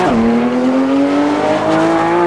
Index of /server/sound/vehicles/vcars/porsche911carrera